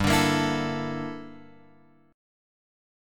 Gm6add9 chord {3 5 x 3 5 5} chord